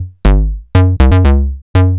TSNRG2 Bassline 011.wav